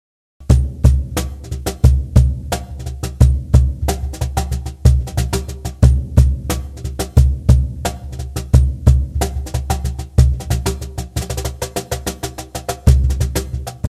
ゆったりしたリズムで低音部のリズムを強調できます。